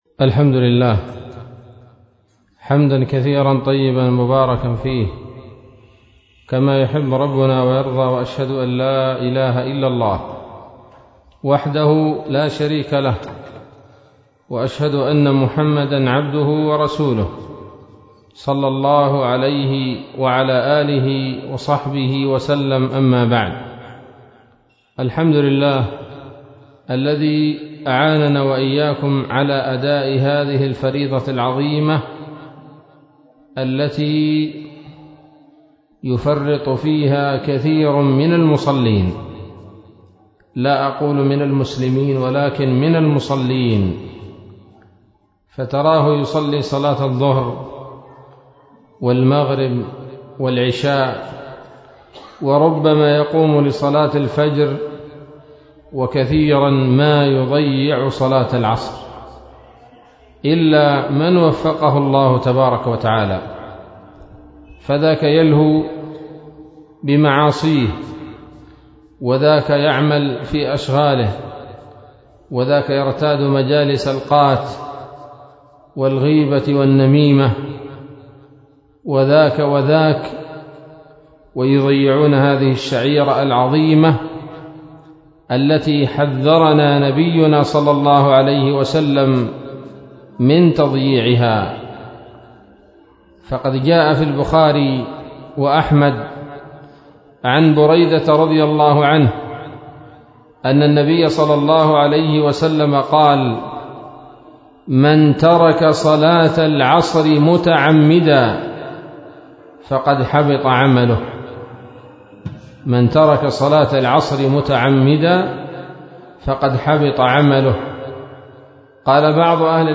كلمة بعنوان